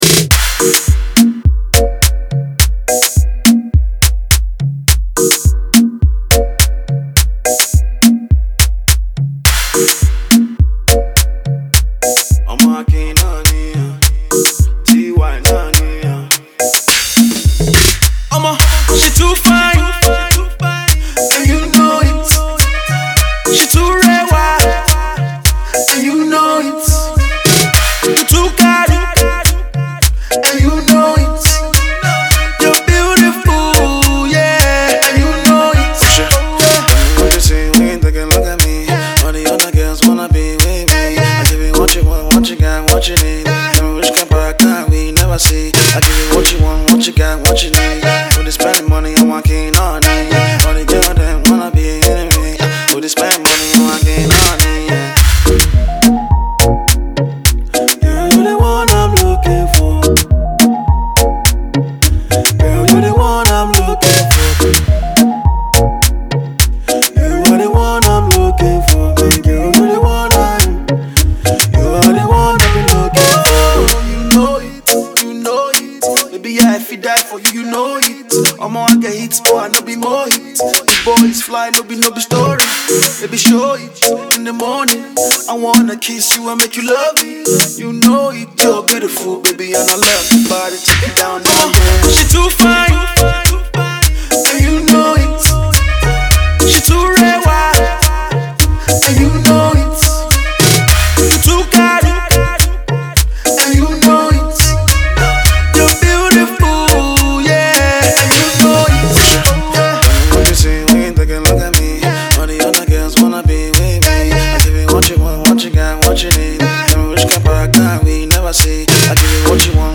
groovy tune